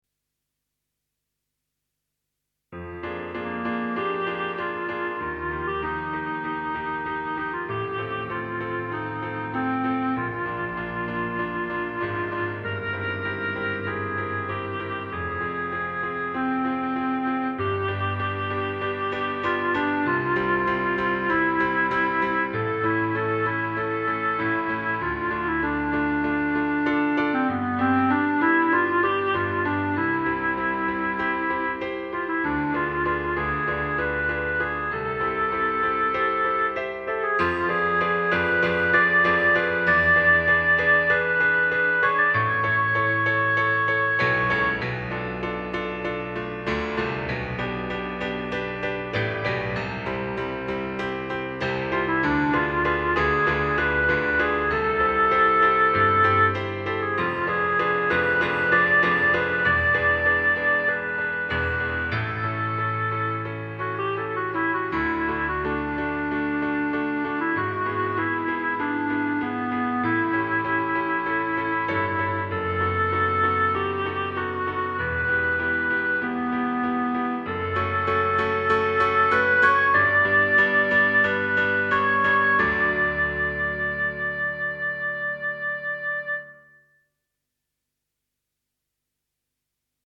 Instrumentation:Melody C, Bb, Eb, pno accompaniment.
Accessible pieces for Solo Instrument with keyboard